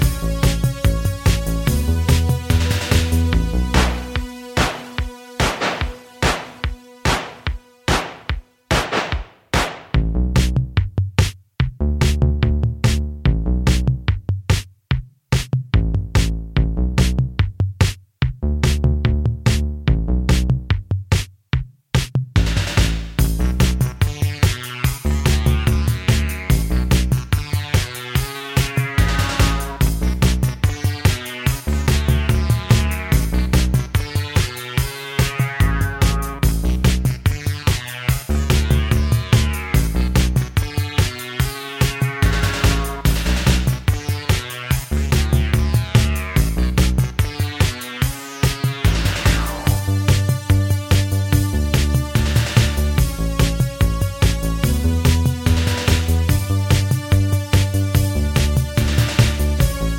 no Backing Vocals Pop (1980s) 3:44 Buy £1.50